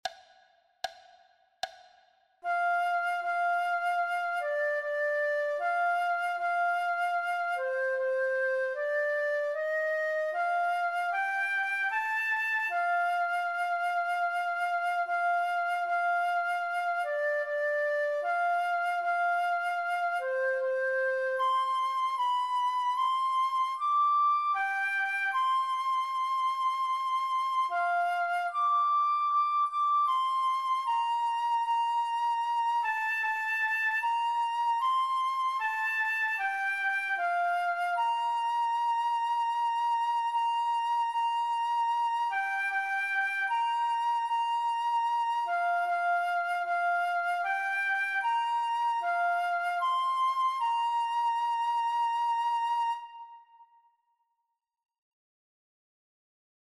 recording of the melody and a lyric sheet for inspiration.
America-the-Beautiful-Melody.mp3